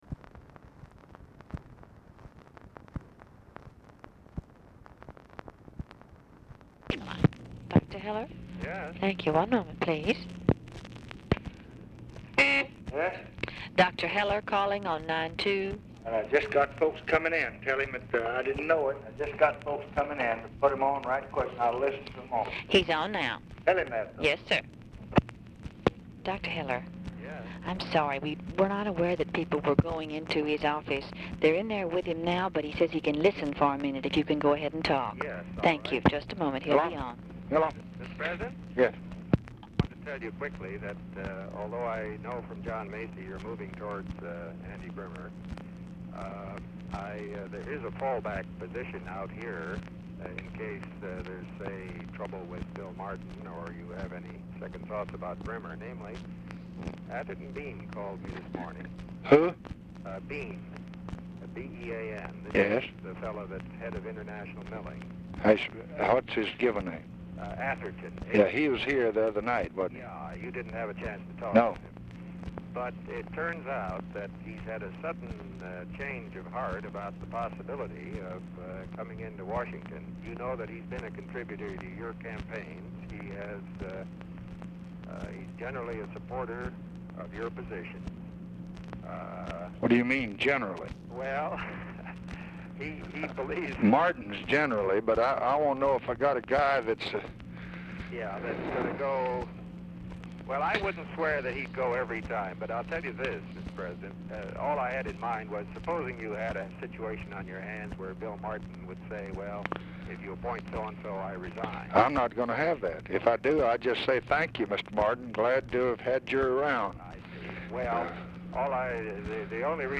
Telephone conversation # 9604, sound recording, LBJ and WALTER HELLER
LBJ TELLS HER HE IS IN A MEETING BUT CAN TALK TO HELLER ON SPEAKERPHONE
Format Dictation belt
Oval Office or unknown location